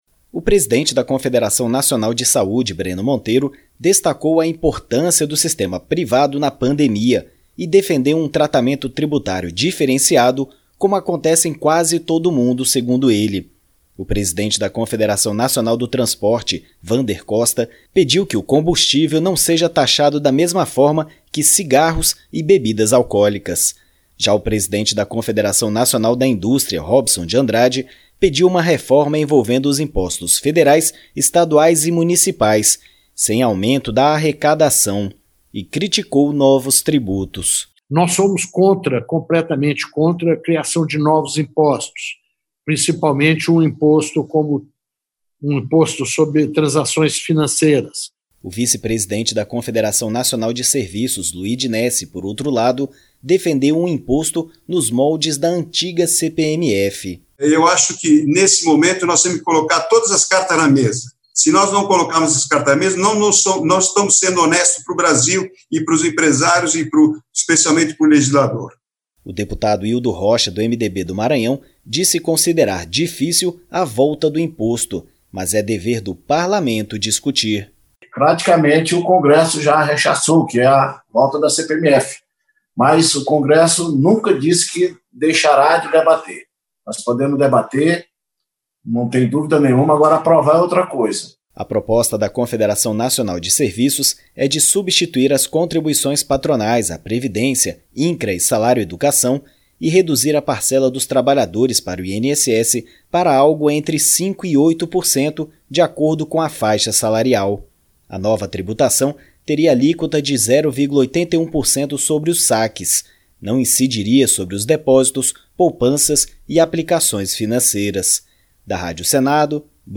As entidades participaram nesta quarta-feira (2) da sexta audiência pública da Comissão Mista da Reforma Tributária. A reportagem